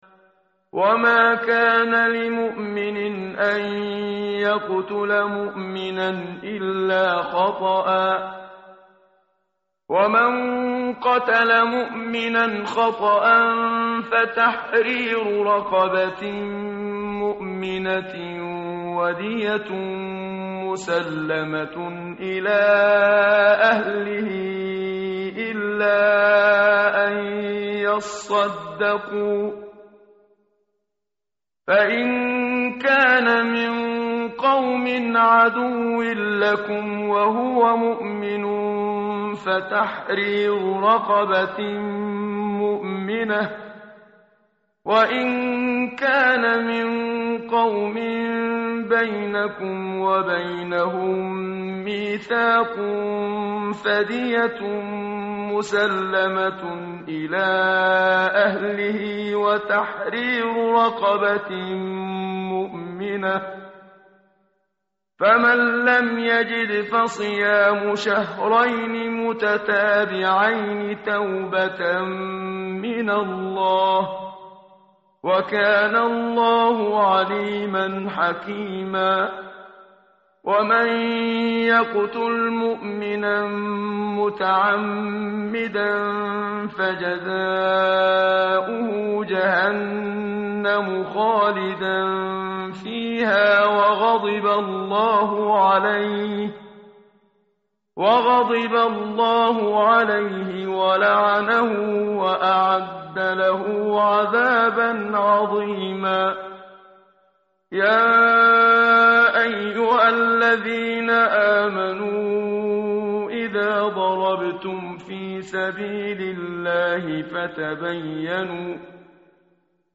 متن قرآن همراه باتلاوت قرآن و ترجمه
tartil_menshavi_page_093.mp3